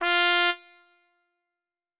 Cow_Life_Sim_RPG/Sounds/SFX/Instruments/Trumpets/doot4.wav at a9e1ed9dddb18b7dccd3758fbc9ca9706f824ea5
doot4.wav